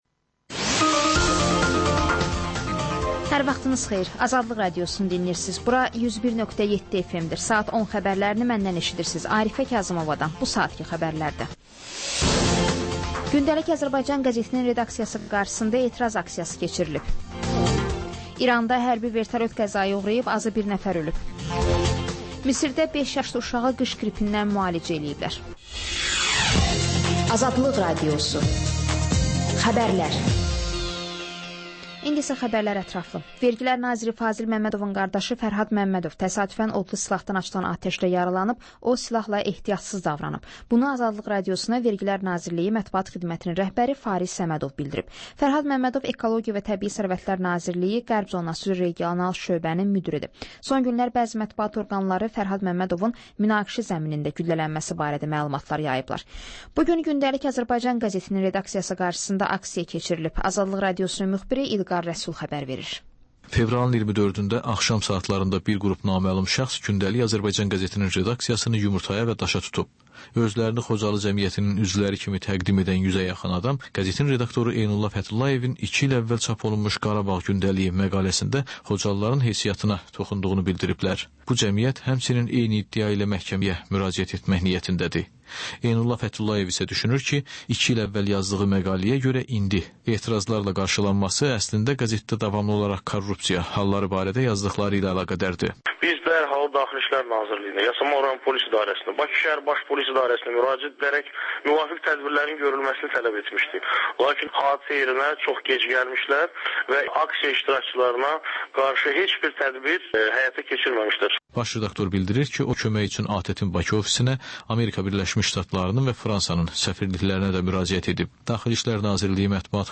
Xəbərlər, reportajlar, müsahibələr. Və: Qafqaz Qovşağı: Azərbaycan, Gürcüstan və Ermənistandan reportajlar.